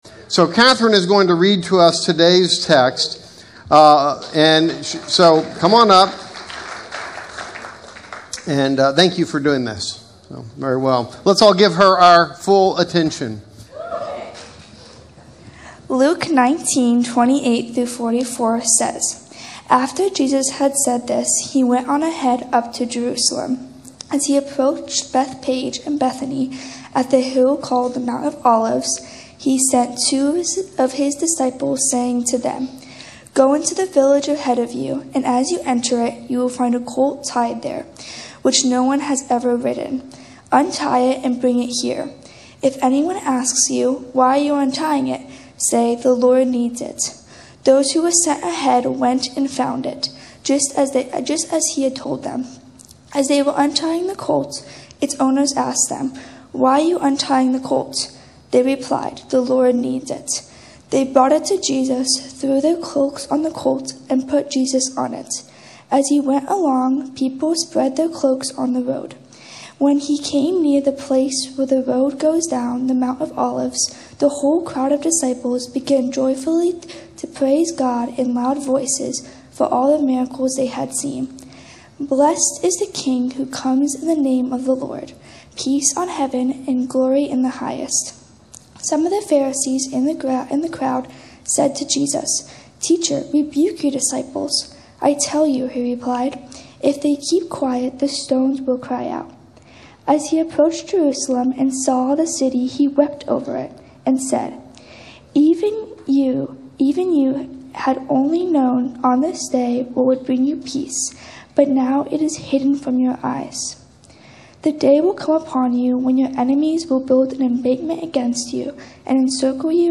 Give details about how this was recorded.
Join us as we celebrate Palm Sunday and explore that well-known ride of Jesus into Jerusalem as well as the stories leading up to it (Luke 18:35–19:44). There we learn how seeing Jesus clearly leads to a life of Jubilee—where generosity, restoration, and peace begin to flow.